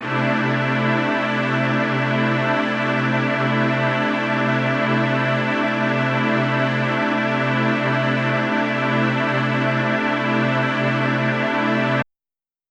SO_KTron-Ensemble-C6:9.wav